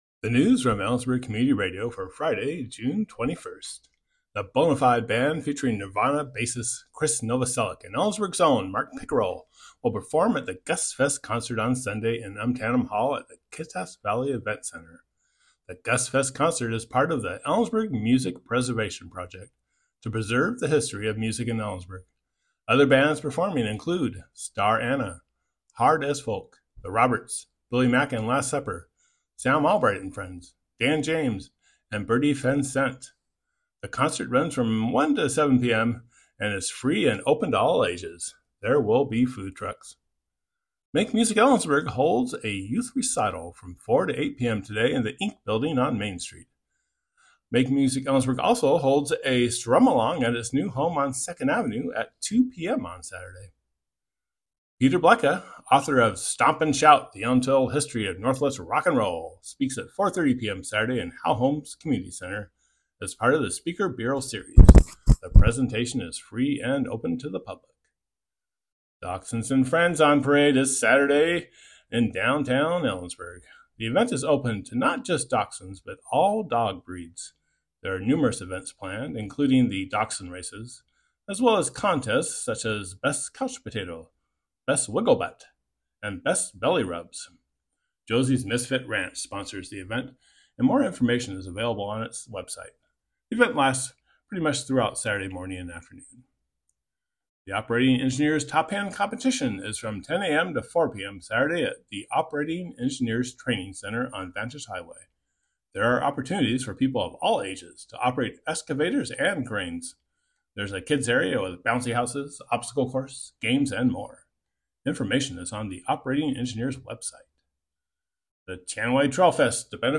Click here to listen to today's newscast.